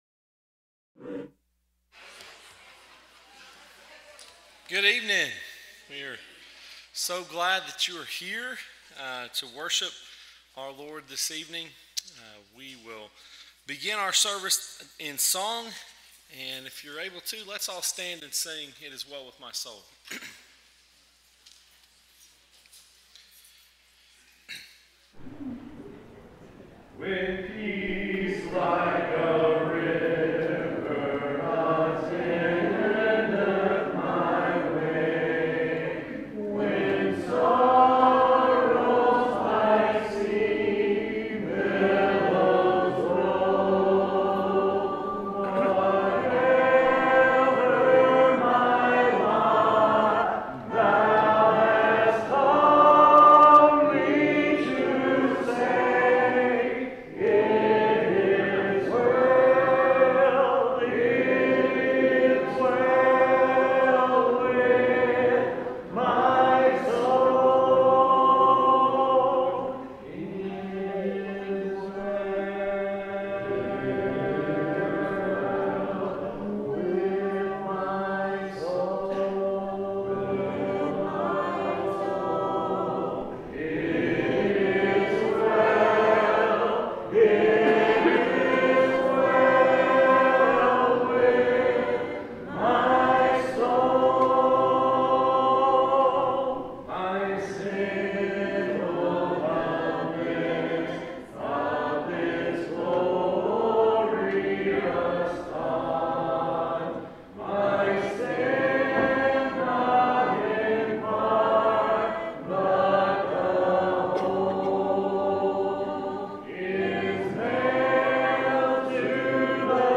Proverbs 3:5-6, English Standard Version Series: Sunday PM Service